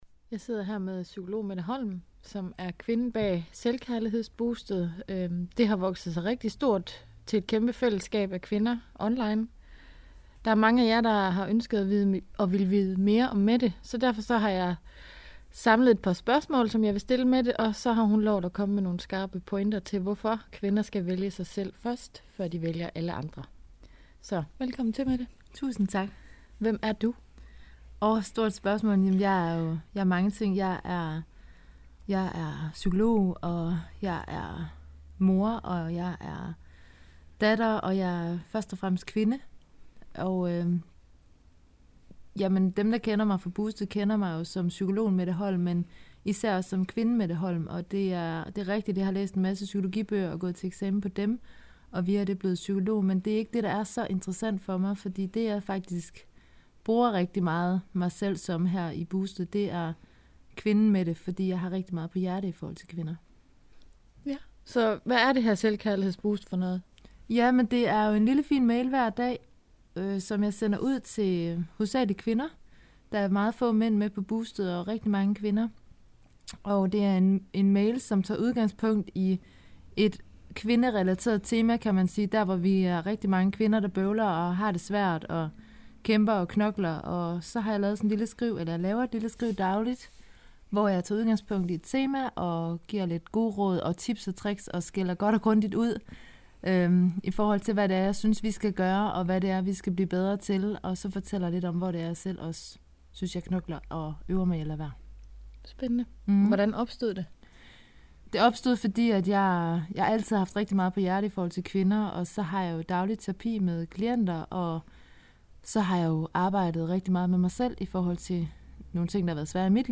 Interview omkring Selvkærligheds Boostet